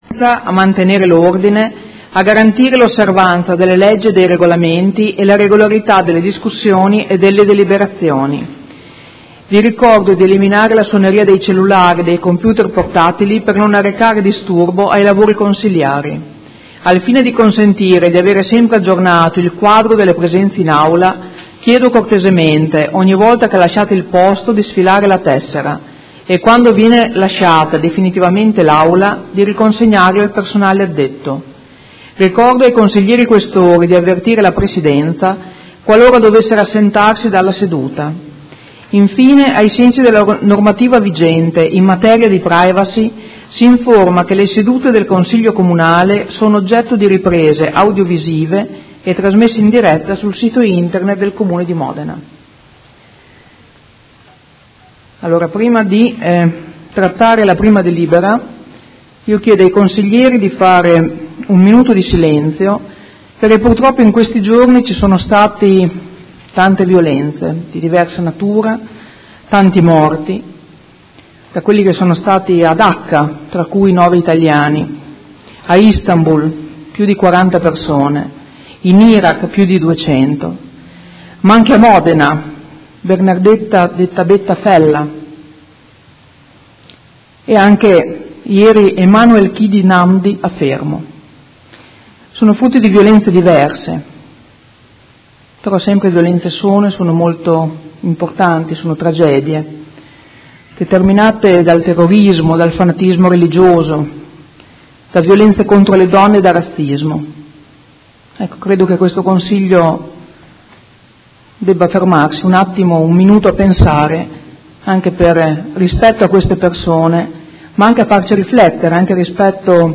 Presidente — Sito Audio Consiglio Comunale
Apertura del Consiglio Comunale e minuto di silenzio in memoria delle morti violente avvenute sia a Dacca, per opera dei terroristi, che in italia per mano di gente violenta